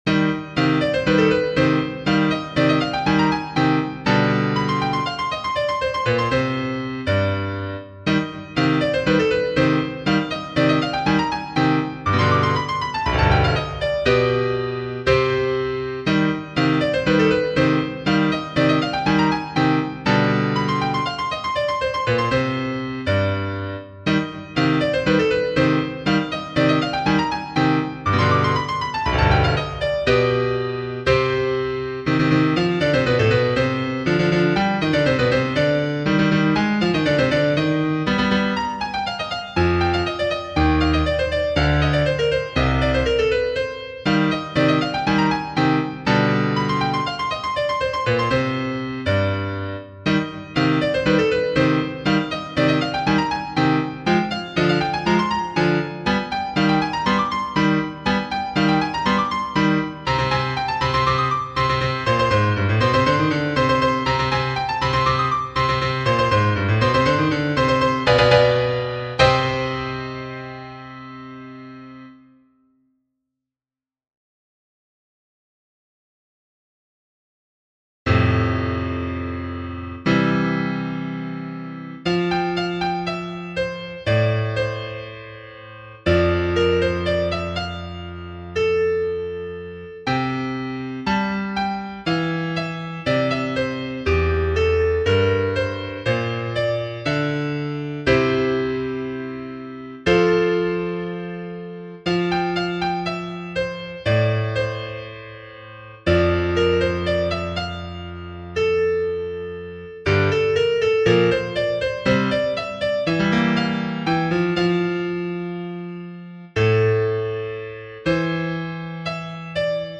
Untitled Piano sonata in C minor.mp3
Untitled_Piano_sonata_in_C_minor.mp3